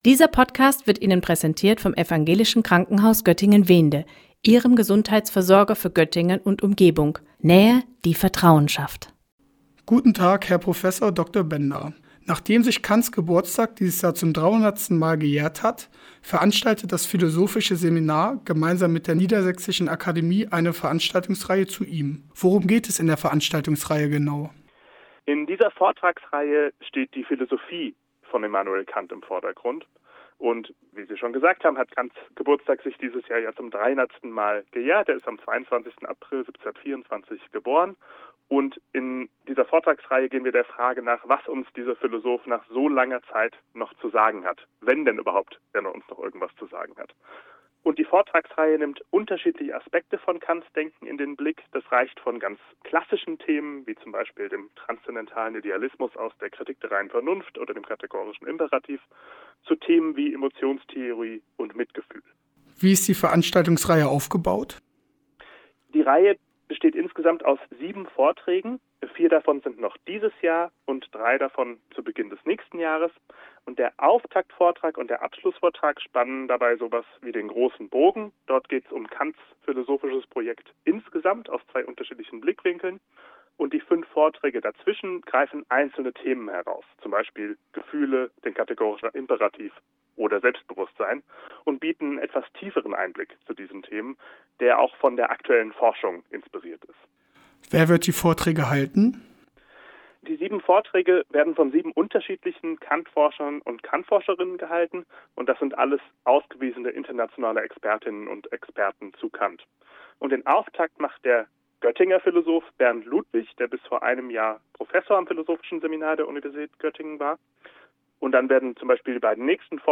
ONLINE_Interview_Kant-playout.mp3